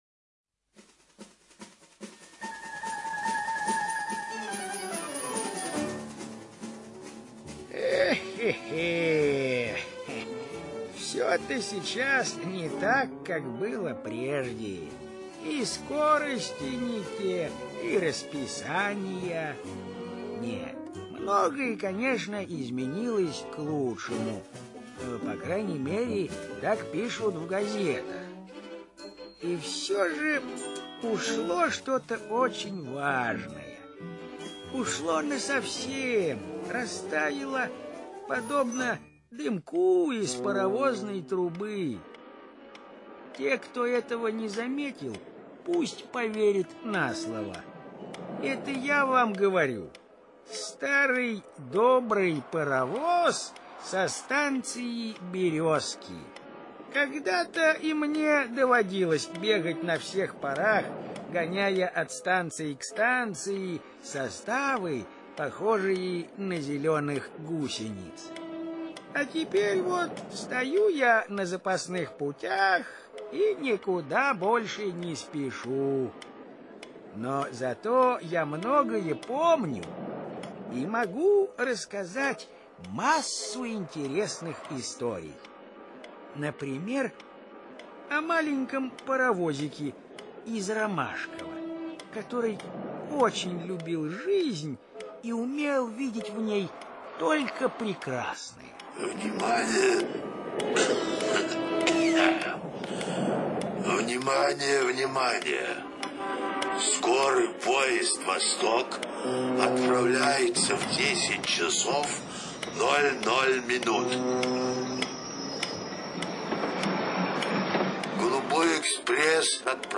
Паровозик из Ромашково - сборник аудиосказок. Добрый и смышленый паровозик занимается тем, что доставляет пассажиров на станцию Ромашково.